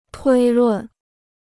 推论 (tuī lùn): inférence; déduction.